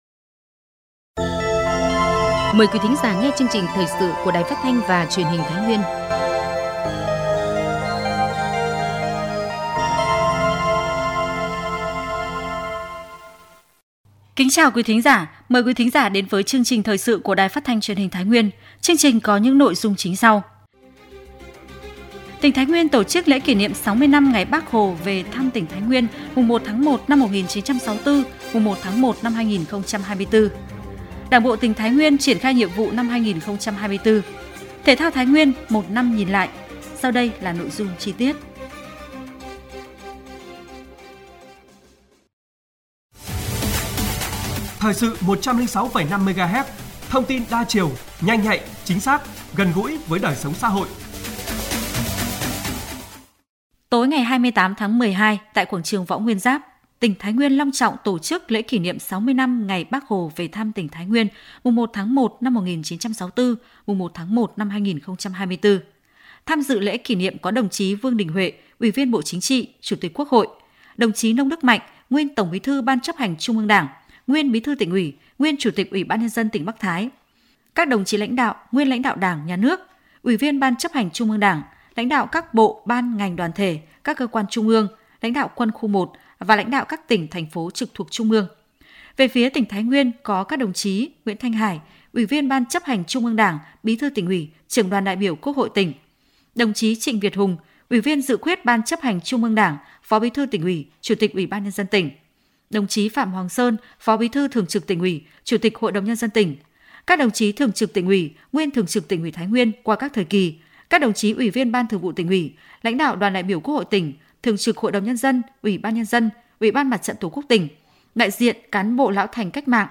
Thời sự tổng hợp Thái Nguyên ngày 05/01/2024